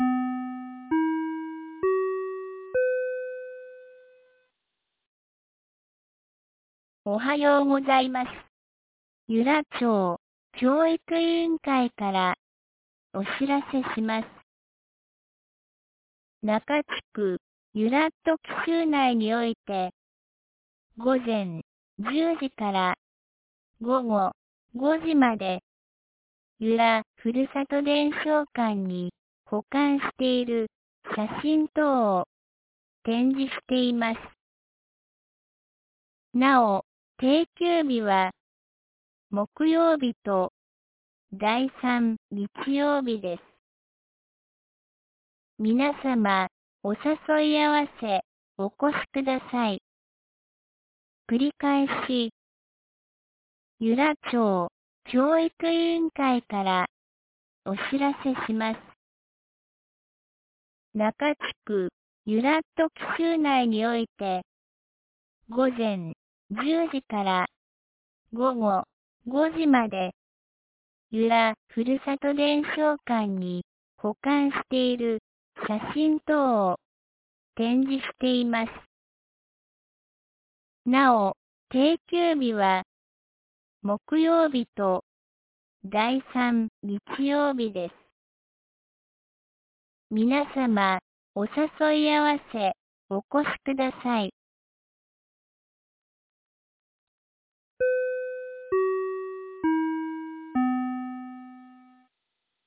2020年10月14日 07時52分に、由良町から全地区へ放送がありました。